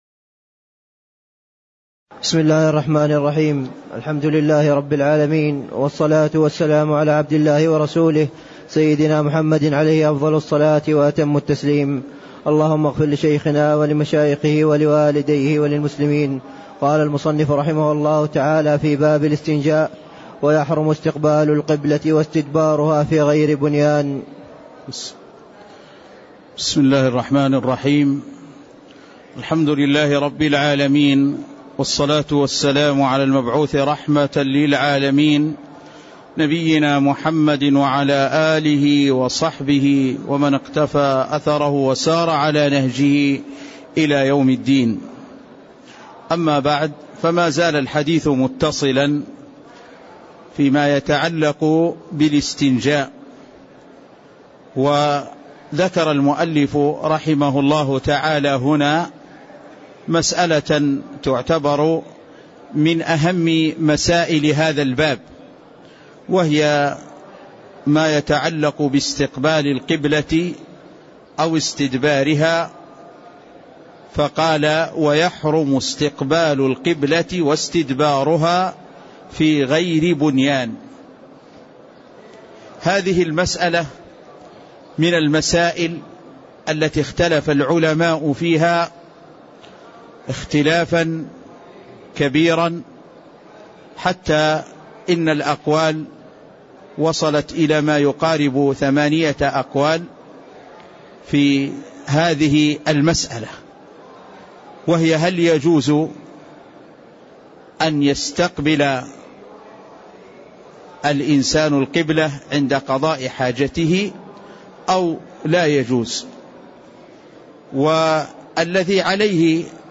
تاريخ النشر ٢٥ ربيع الثاني ١٤٣٥ هـ المكان: المسجد النبوي الشيخ